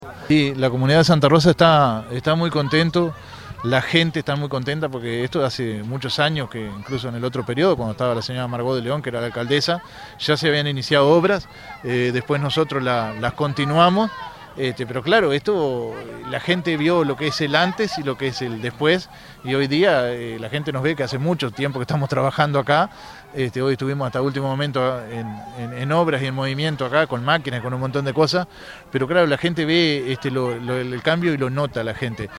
ramiro_azor_alcalde_de_santa_rosa_0.mp3